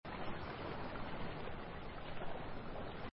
Ocean
music_ocean.B3qXSv6H.mp3